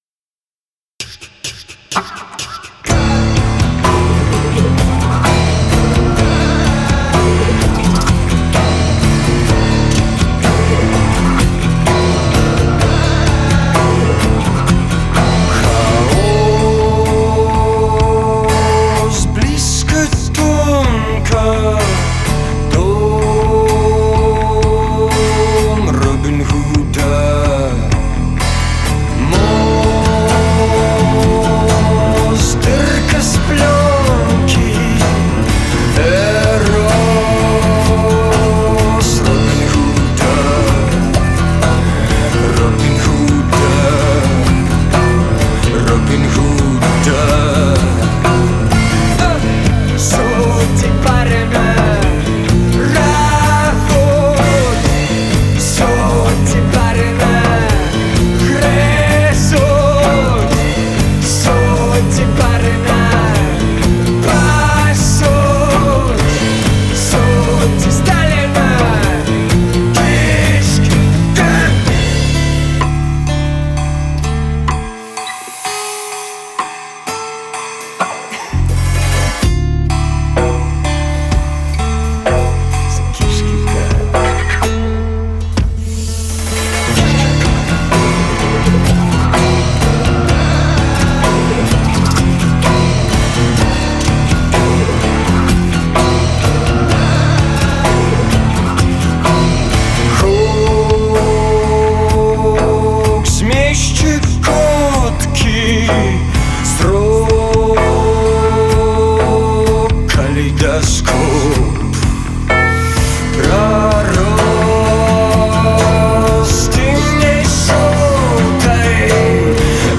вакал, гітара, музыка, тэксты, эфекты